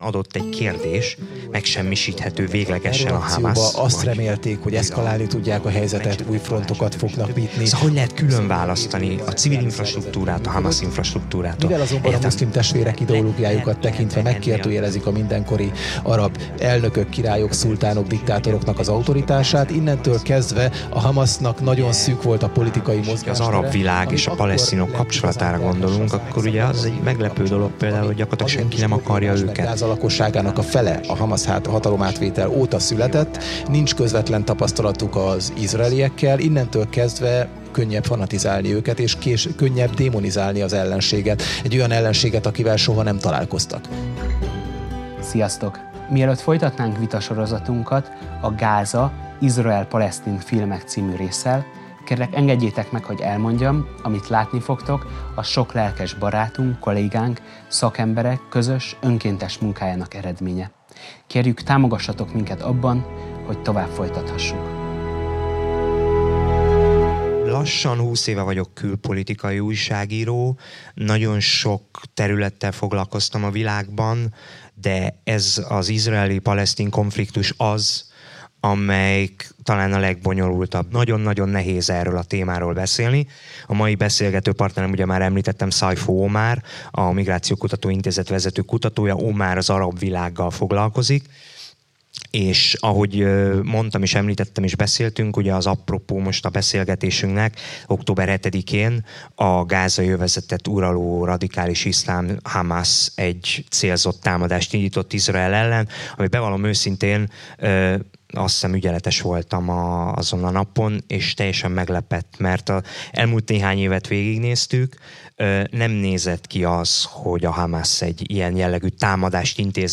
amelyet a 2024-es BIDF-en rögzítettünk